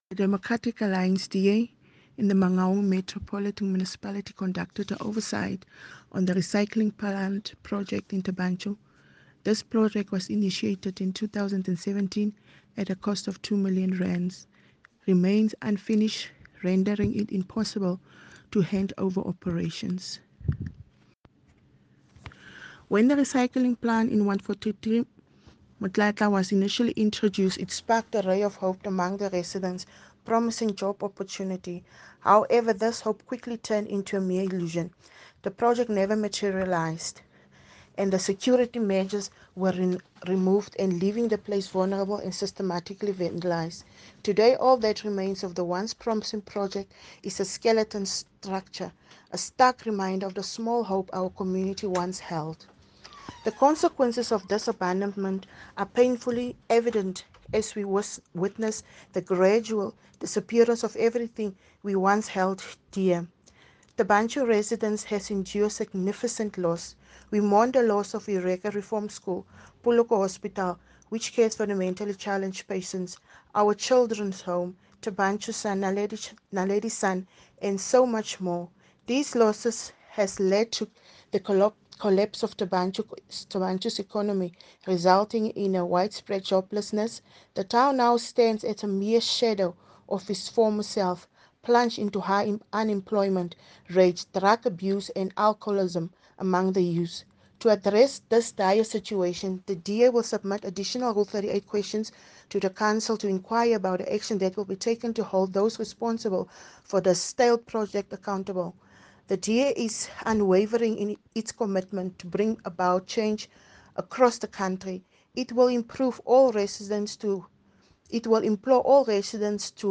English soundbite by Cllr Raynie Klaasen and